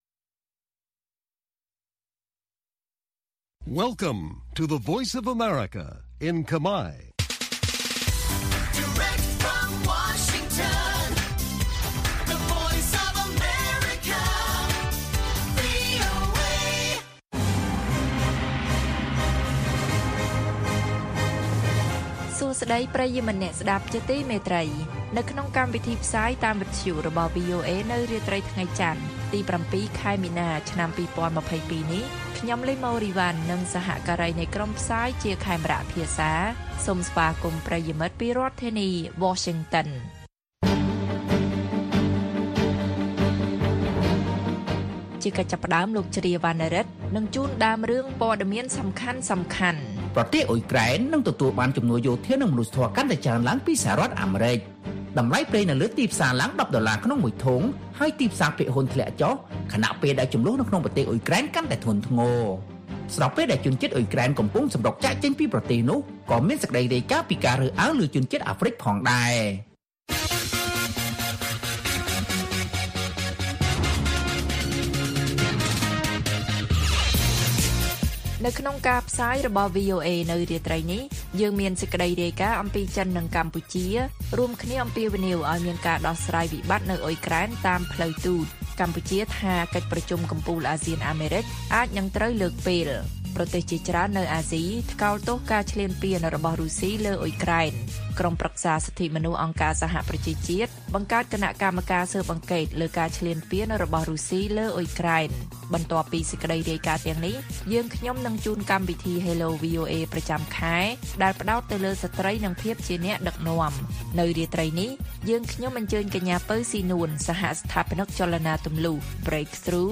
ព័ត៌មានពេលរាត្រី៖ ៧ មីនា ២០២២